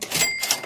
cash.wav